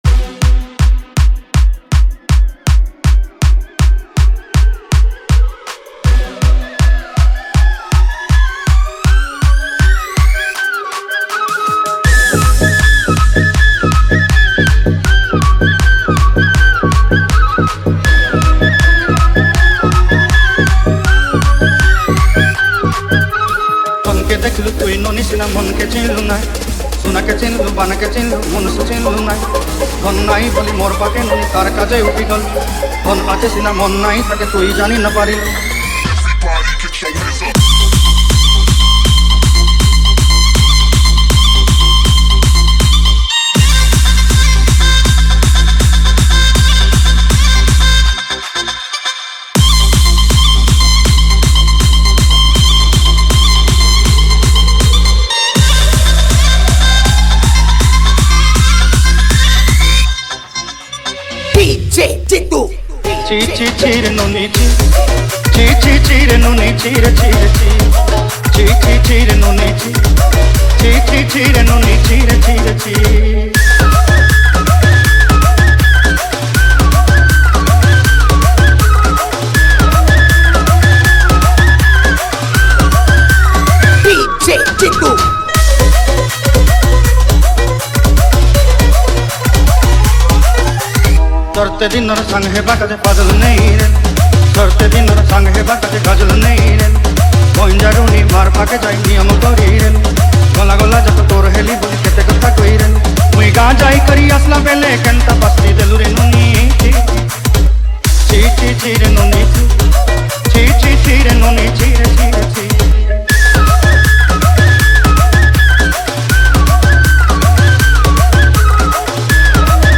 Category:  Sambalpuri Dj Song 2025